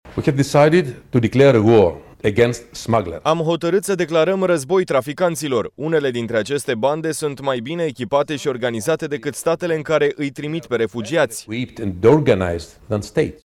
Între timp, într-un interviu acordat BBC, comisarul european pentru Migrație,  Dimitris Avramopoulos, amenință că toți cei care nu vor căpăta statutul de refugiat din calea războiului vor fi trimiși în țările de origine.
26-aug-18-comisar-european-tradus-migratie-.mp3